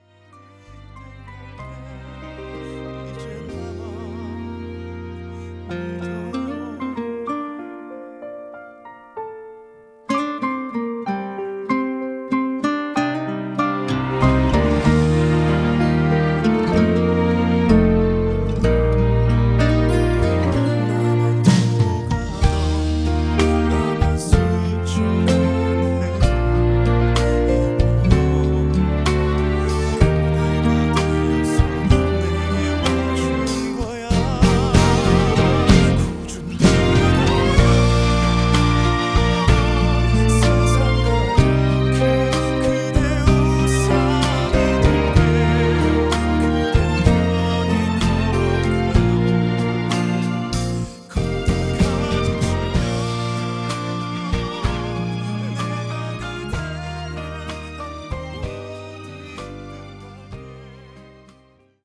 음정 -1키 4:03
장르 가요 구분 Voice Cut